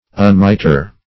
Search Result for " unmiter" : The Collaborative International Dictionary of English v.0.48: Unmiter \Un*mi"ter\, Unmitre \Un*mi"tre\, v. t. [1st pref. un- + miter.]